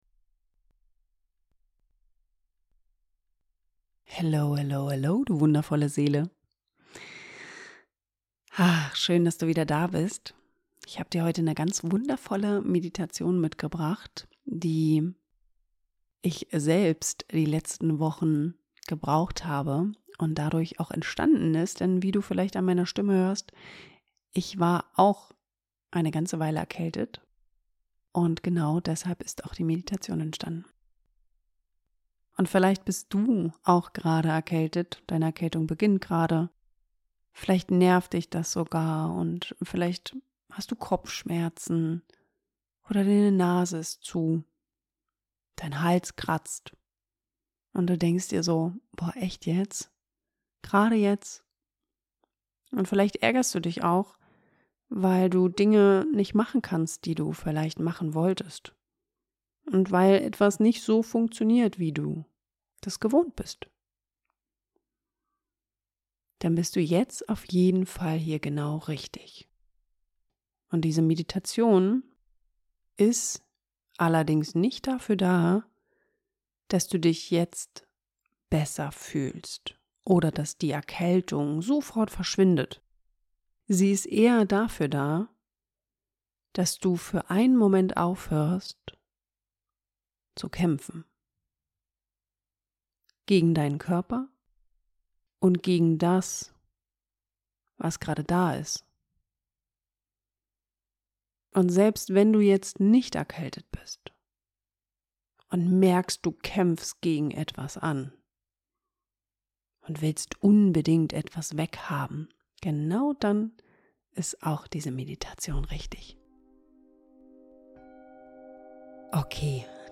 Ich war selbst vor einiger Zeit krank und du hörst es eventuell an meiner Stimme in der Medi.
Und genau dafür ist diese Meditation.